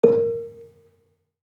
Gambang-A#3-f.wav